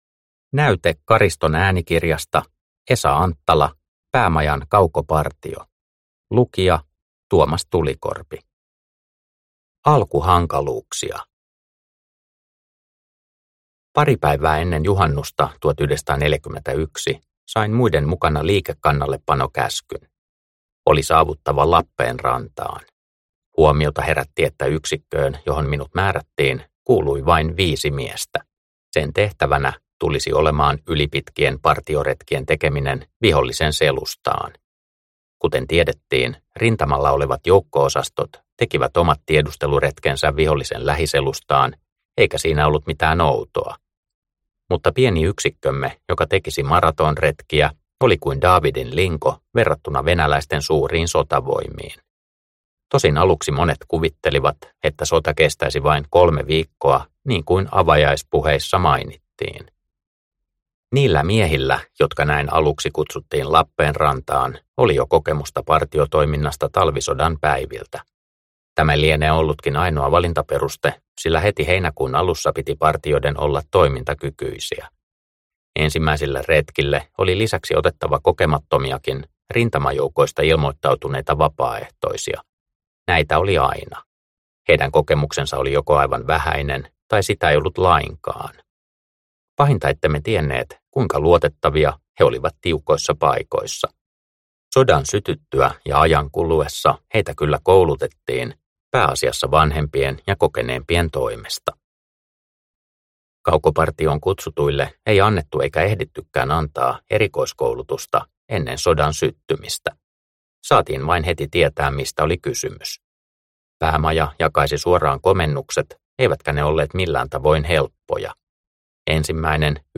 Päämajan kaukopartio – Ljudbok – Laddas ner